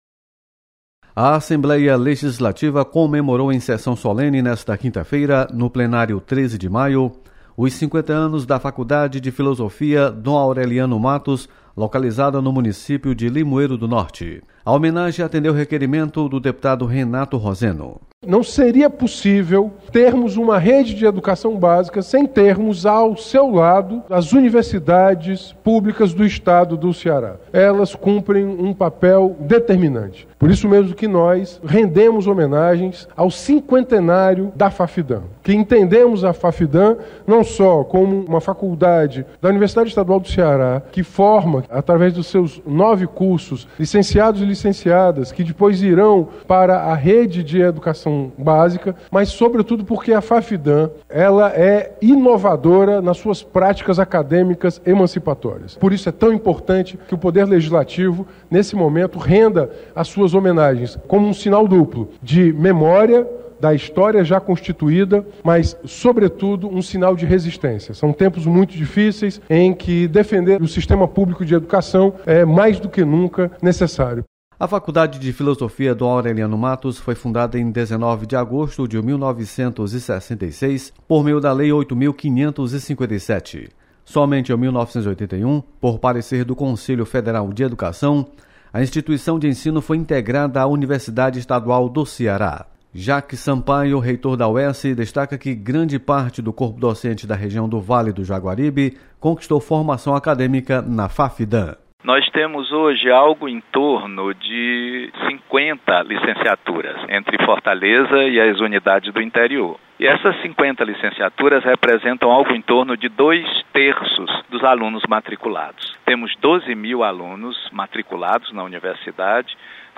Você está aqui: Início Comunicação Rádio FM Assembleia Notícias Sessão solene